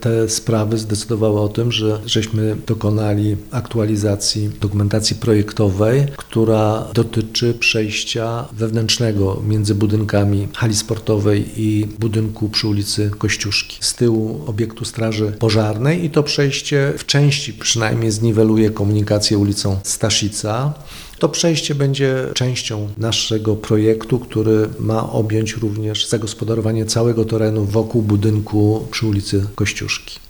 – Ścieżka poprawi funkcjonalność szkoły oraz bezpieczeństwo uczniów i nauczycieli – powiedział Waldemar Wrześniak, nowosolski wicestarosta: